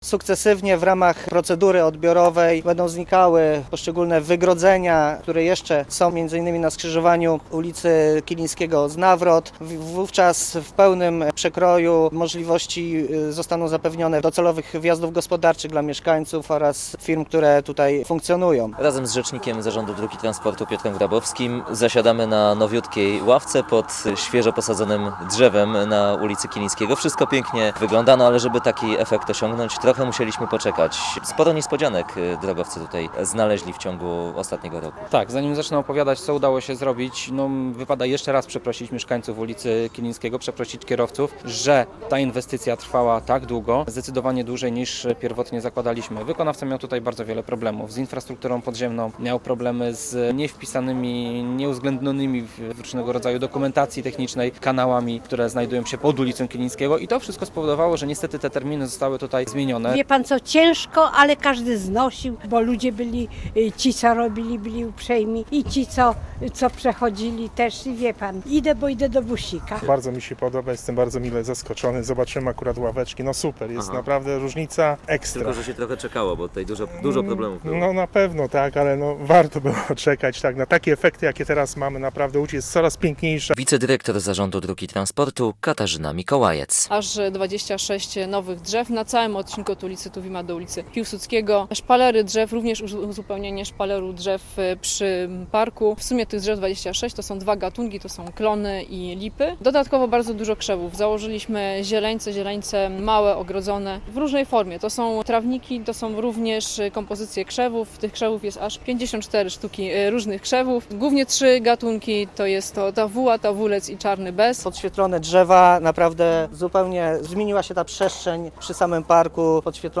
Posłuchaj relacji i dowiedz się więcej: Nazwa Plik Autor Kilińskiego po przebudowie audio (m4a) audio (oga) ZDJĘCIA, NAGRANIA WIDEO, WIĘCEJ INFORMACJI Z ŁODZI I REGIONU ZNAJDZIESZ W DZIALE “WIADOMOŚCI”.